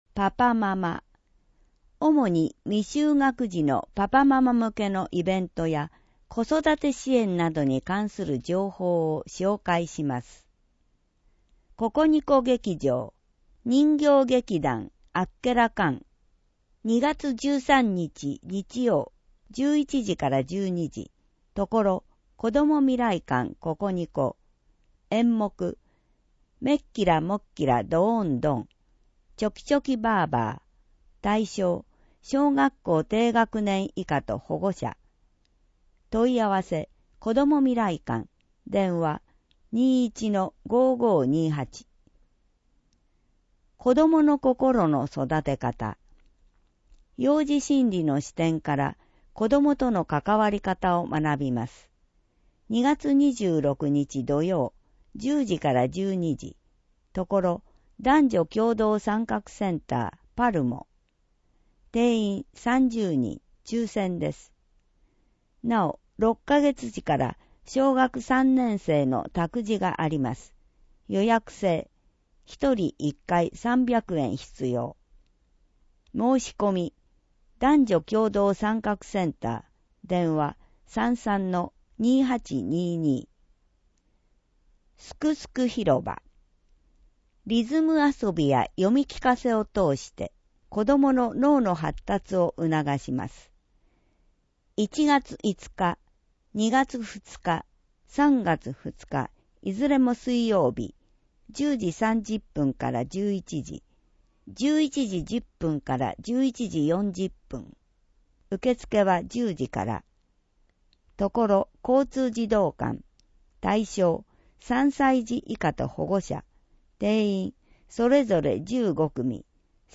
• 「広報とよはし」から一部の記事を音声でご案内しています。視覚障害者向けに一部読み替えています。